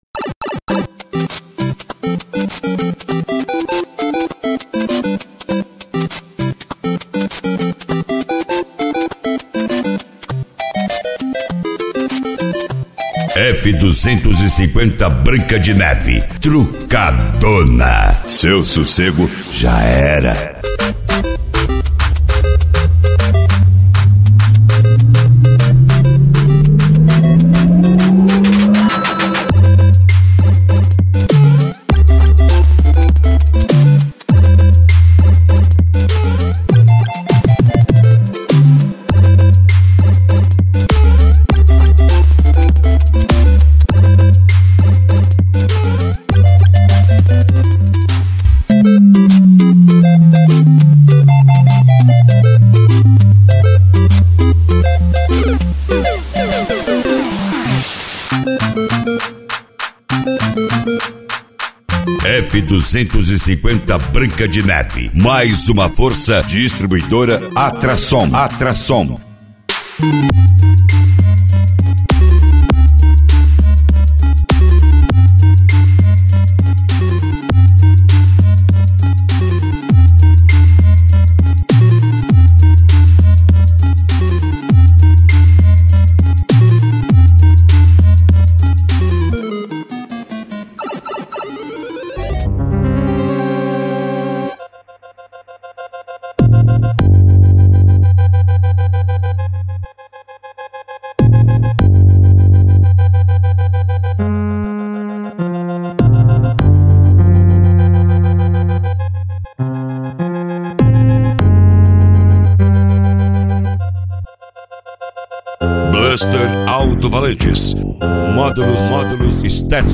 Funk Nejo
Hard Style
PANCADÃO